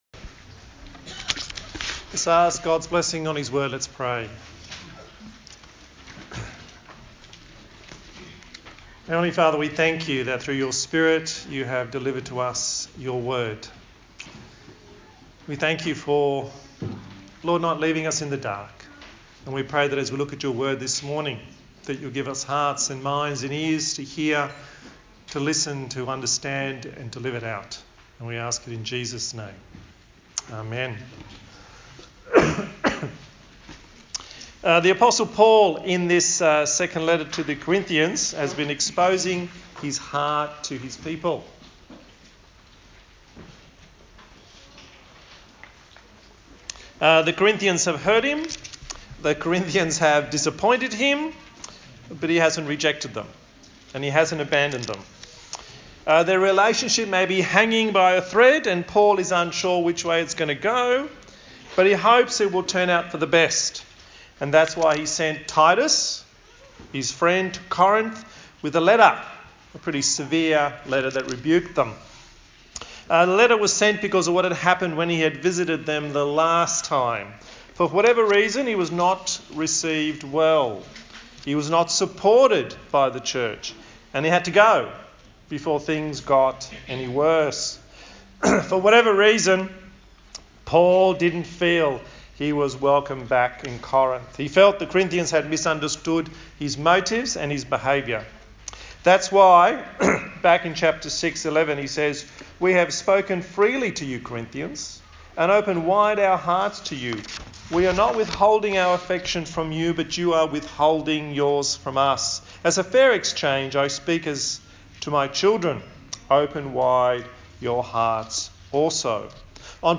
A sermon in the series on the book of 2 Corinthians
Service Type: Sunday Morning